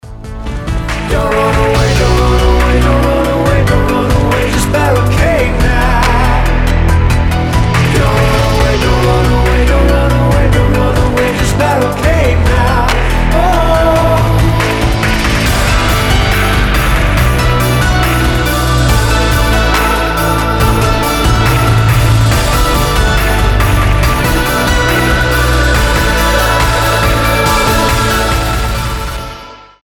мужской вокал
Electronic
synthwave
Synth Rock
post-punk revival